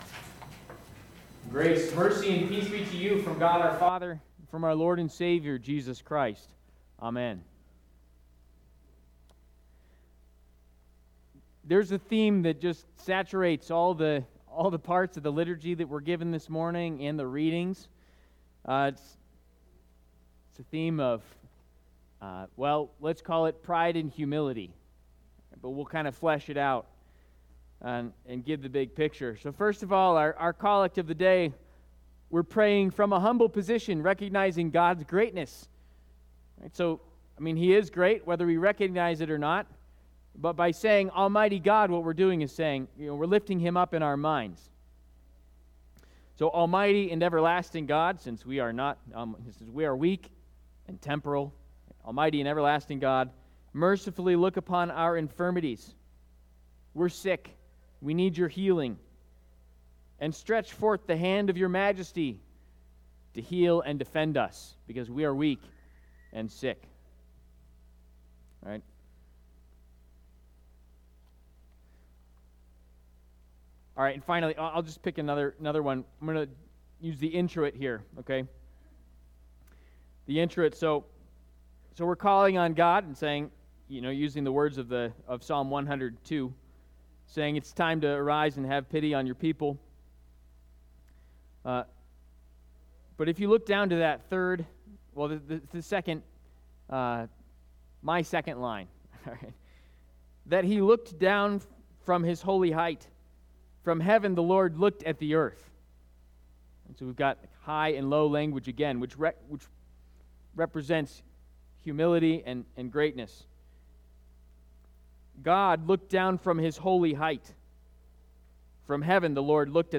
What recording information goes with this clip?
Third Sunday of Epiphany&nbsp